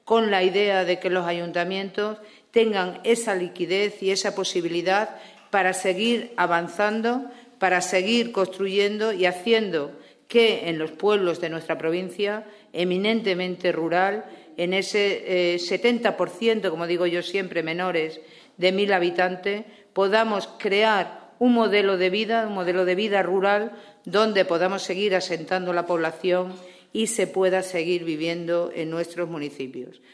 CORTES DE VOZ
17/05/2016.- Se trata de una iniciativa novedosa en la provincia y que viene a ser manifestación del decidido apoyo del Equipo de Gobierno a las Entidades Locales de la provincia en todos los ámbitos, tal como ha explicado en la presentación la presidenta de la Diputación de Cáceres, Charo Cordero, acompañada del vicepresidente segundo y diputado de Economía y Hacienda, Alfonso Beltrán.